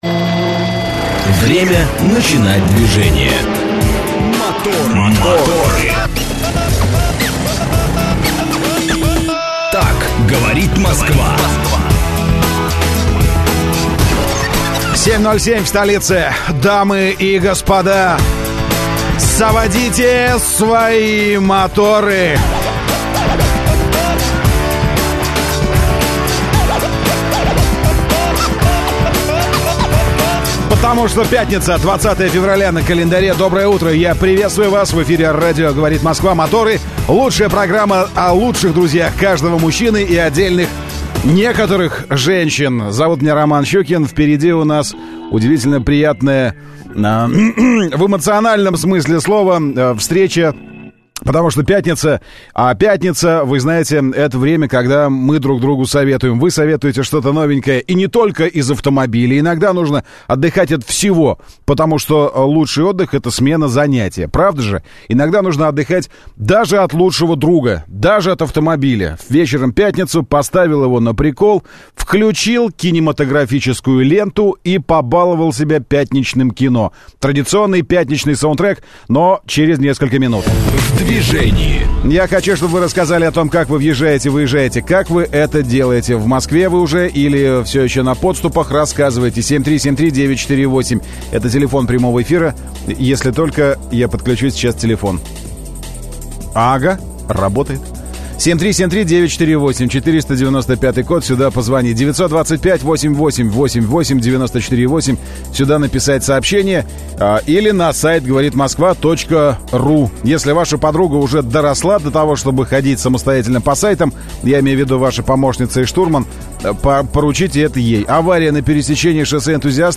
Утренняя программа для водителей и не только. Ведущие рассказывают о последних новостях автомобильного мира, проводят со слушателями интерактивные «краш-тесты» между популярными моделями одного класса, делятся впечатлениями от очередного тест-драйва.